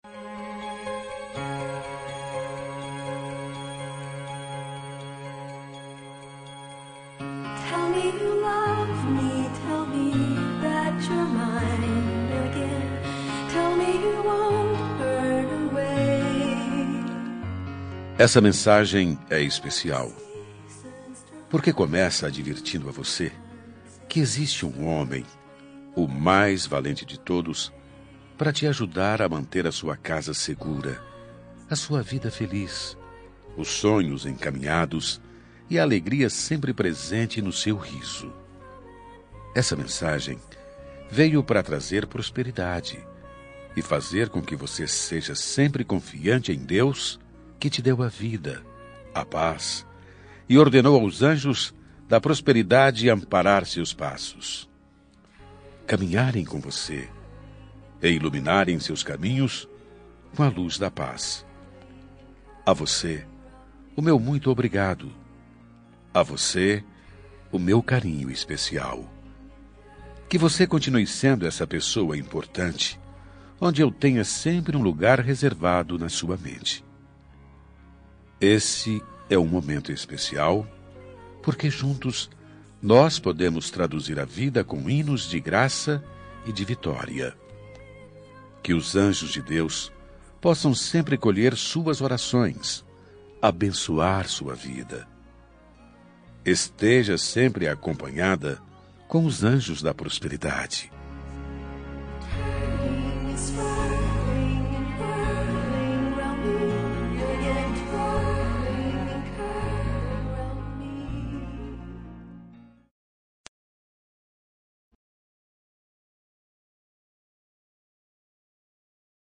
Aniversário Religioso – Voz Masculina – Cód: 69 – Anjos